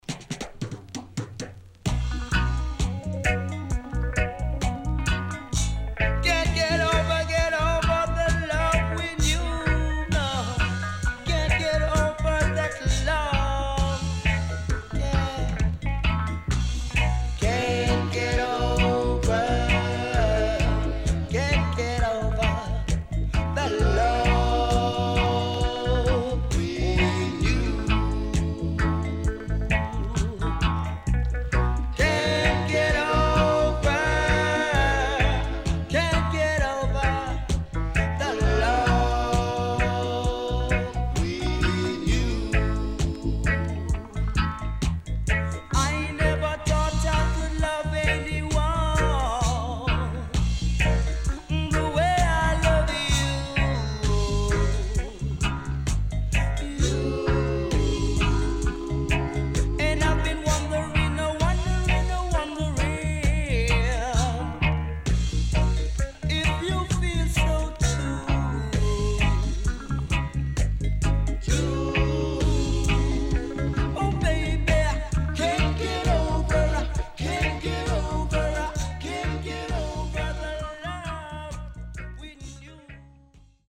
SIDE A:少しチリノイズ、プチノイズ入ります。